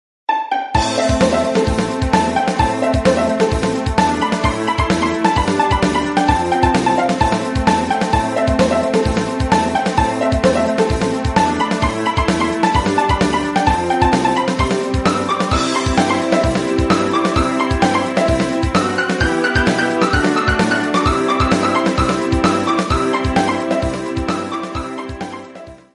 Kategorie Elektroniczne